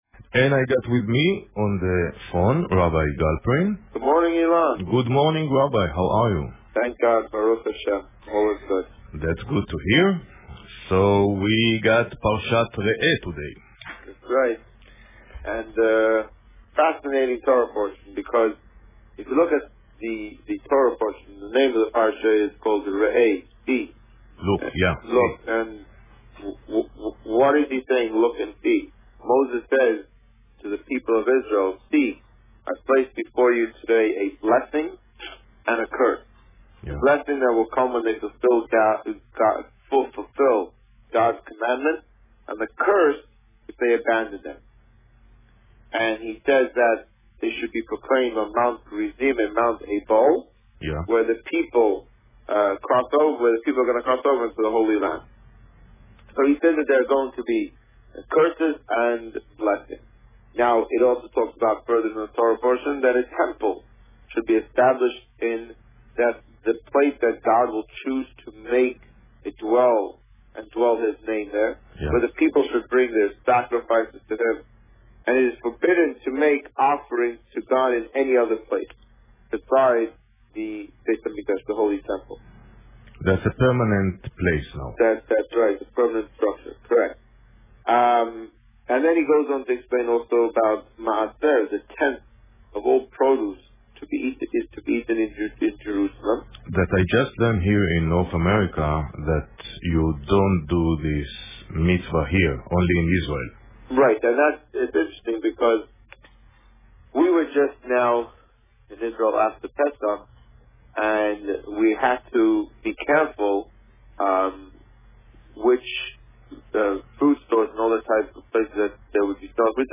You are here: Visitor Favourites The Rabbi on Radio The Rabbi on Radio Parsha Re'eh Published: 21 August 2014 | Written by Administrator This week, the Rabbi spoke about Parsha Re'eh.